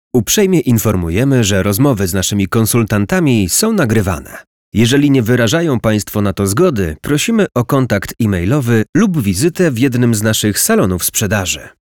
Nagrania powitań telefonicznych
Przykładowe powitania telefoniczne moim głosem